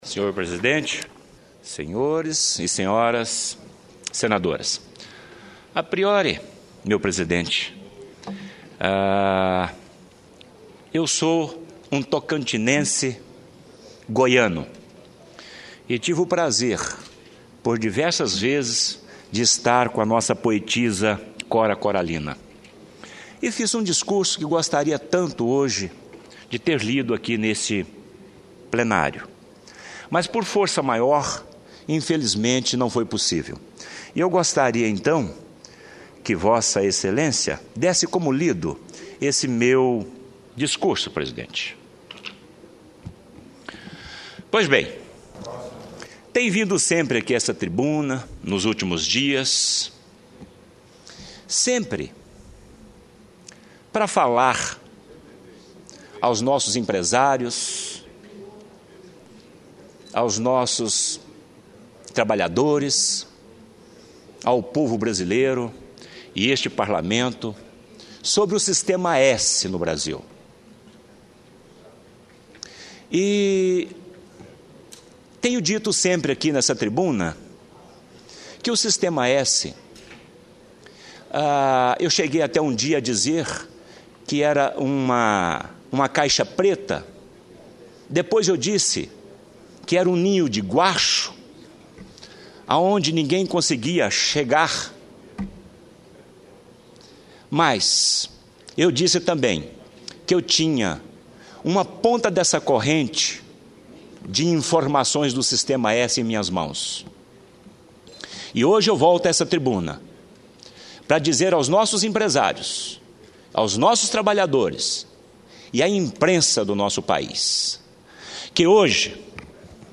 Ataídes Oliveira lê relatório do TCU sobre possíveis irregularidades no sistema S
O senador Ataídes de Oliveira (Bloco/PSDB-TO) leu alguns trechos do relatório do Tribunal de Contas da União (TCU) em resposta a requerimento de sua autoria sobre o Sistema S.